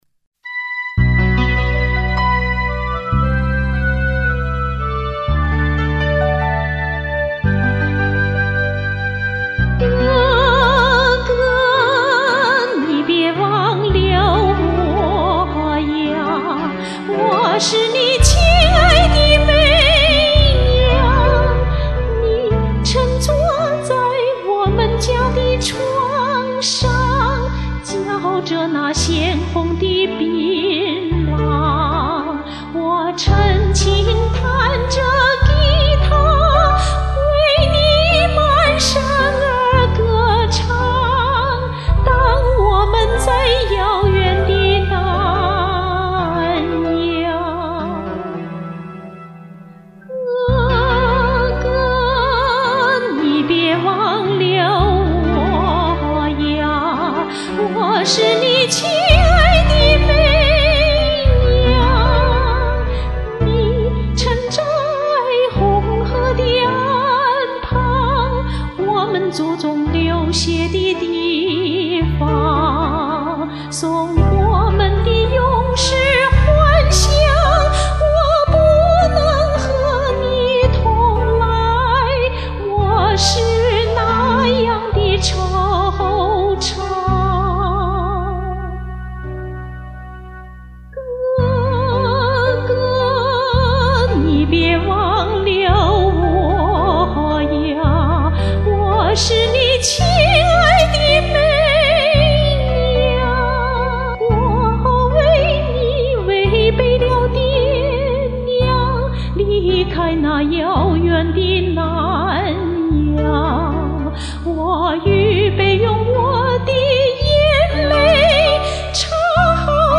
這歌幾年唱過，當時沒看歌譜，且伴奏不好跟，不少地方唱錯，但那時的嗓子明顯比現在水靈。